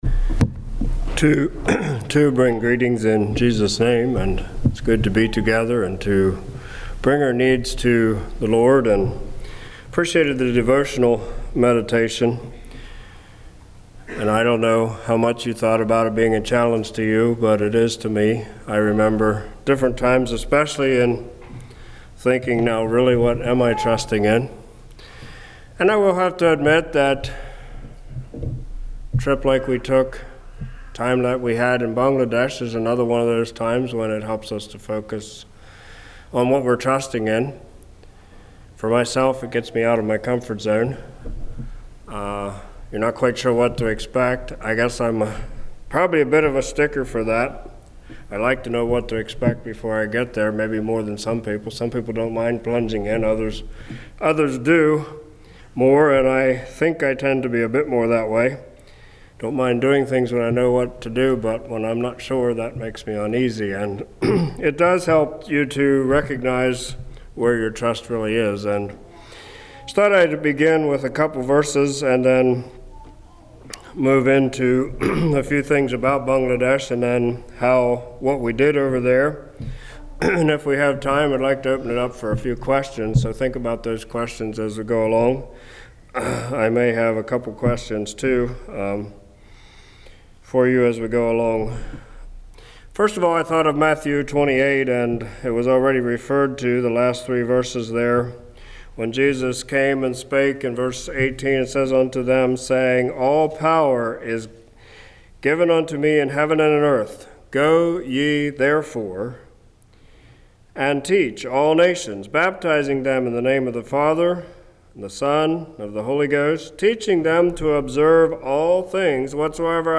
Sermons
Ridge View | Tent Meetings 2023